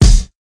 Kick (1).wav